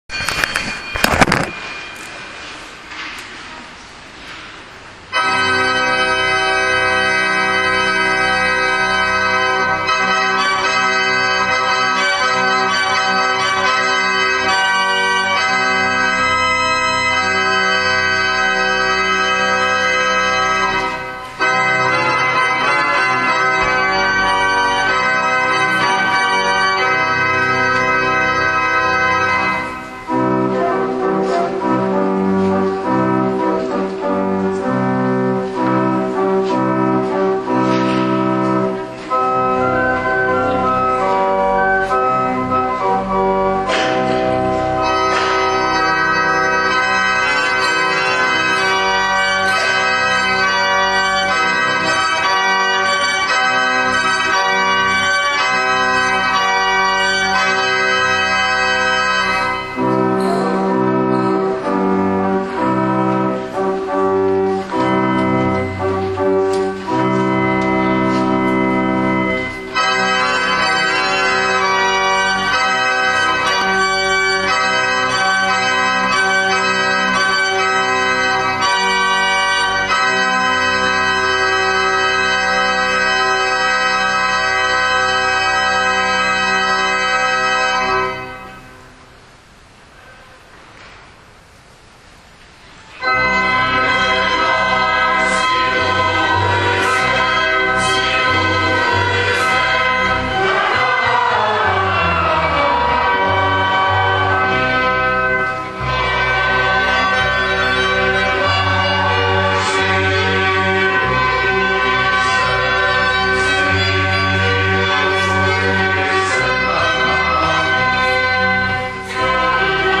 Představte si, že jste u rozhlasového přijímače, pustili jste ho a nyní máte možnost poslouchat či neposlouchat rozhlasový přenos ze štědrovečerní mše svaté v Ostrožské Lhotě. A jak u takových přenosů bývá zvykem, moderátoři do nich "nekecají" a vše nechávají na posluchačích.
varhany
zpívat lhotský farní sbor (odhaduji, jak jsem viděl, posílený o několik hlasů i muzikantů)